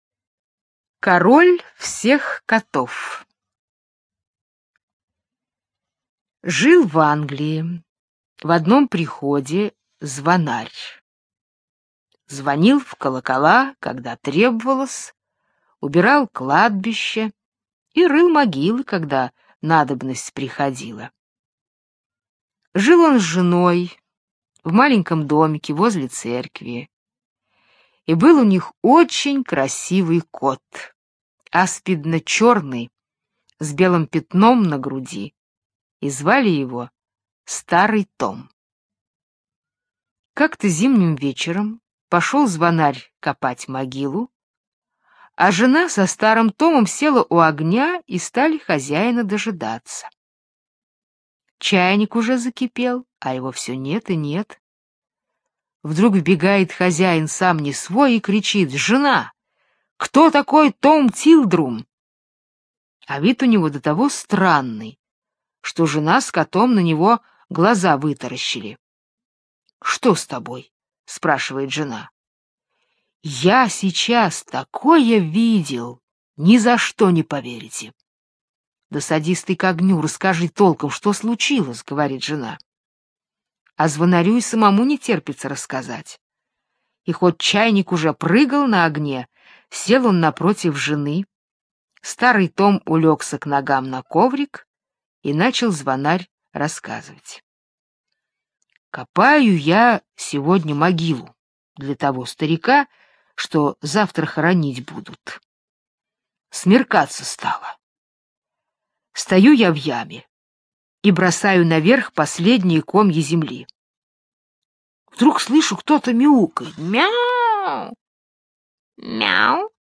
Король всех котов - британская аудиосказка - слушать онлайн